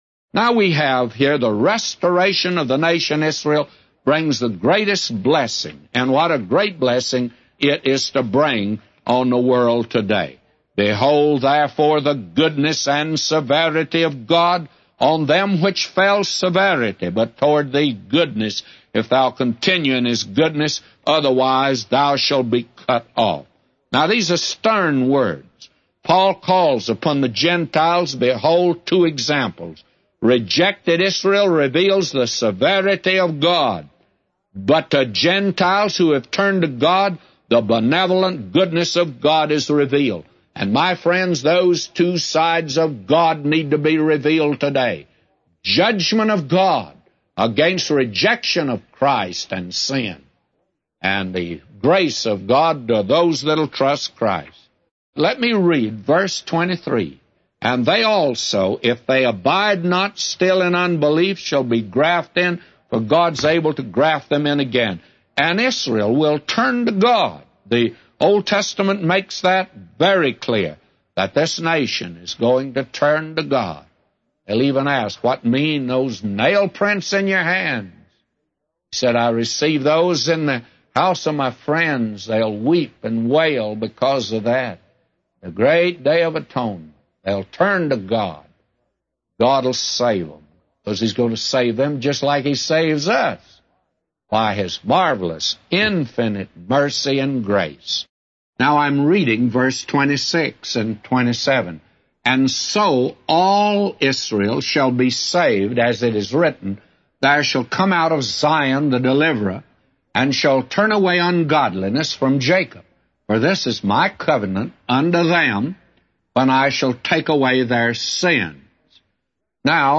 A Commentary By J Vernon MCgee For Romans 11:22-36